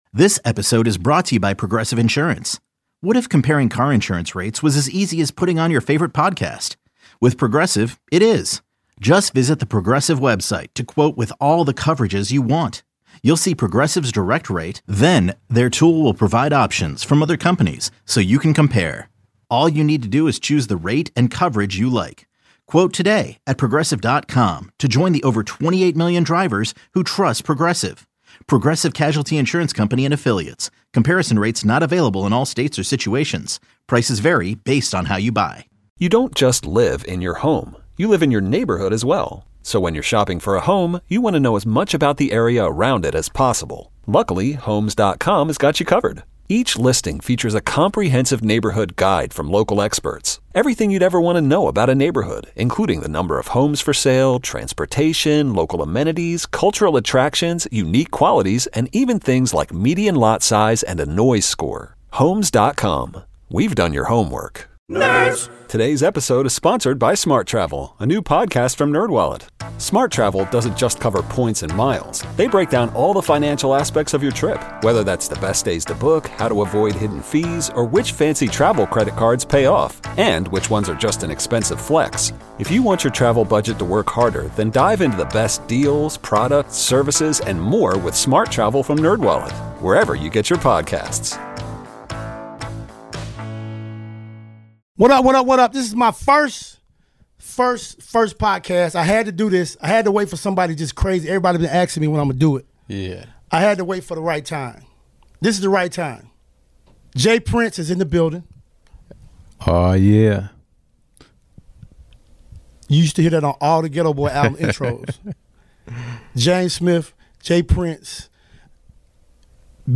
A listener called in and said she does not allow her daughter to trick or treat and has not allowed her to for the past 3-4 years!